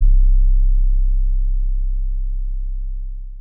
pbs - add distortion [ Sub ].wav